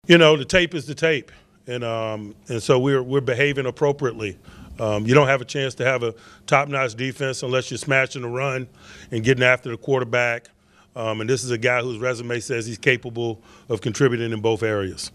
The coach says the Steelers had an obvious hole on the defensive front and teams exploited it last season.